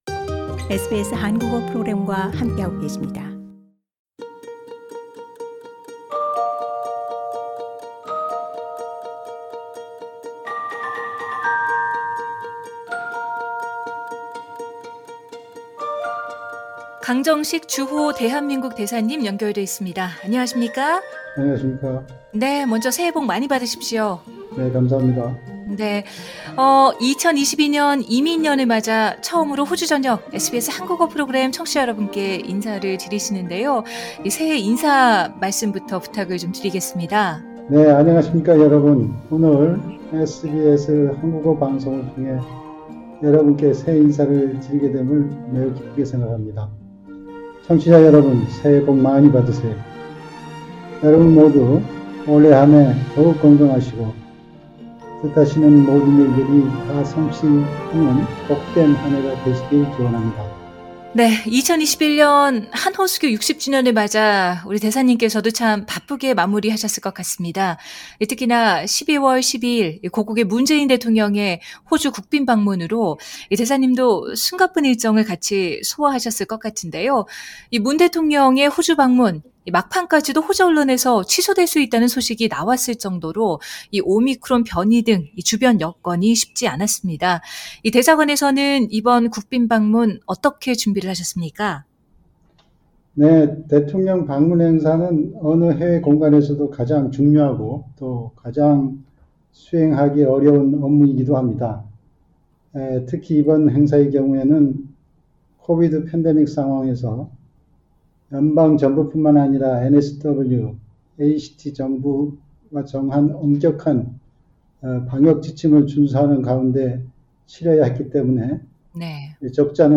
신년 특집 인터뷰: 강정식 주호 한국 대사 “새해 복 많이 받으세요”